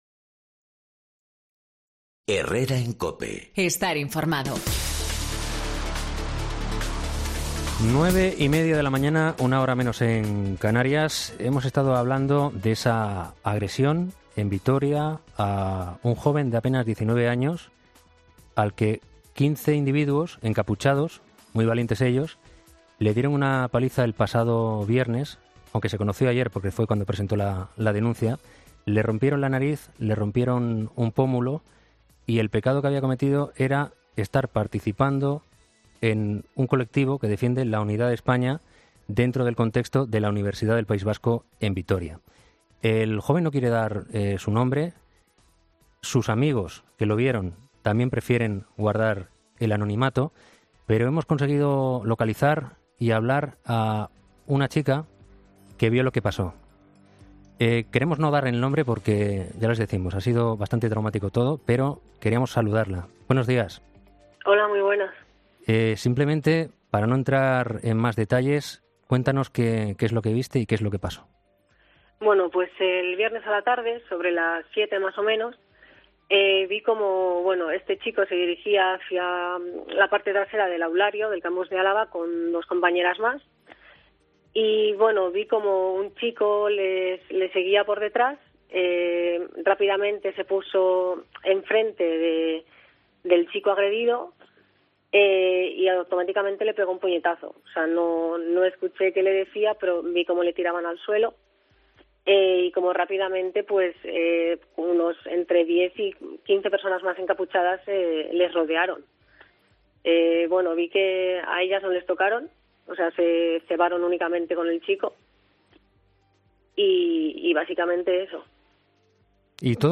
Escucha el testimonio de una testigo de la brutal agresión a un estudiante en la universidad de Vitoria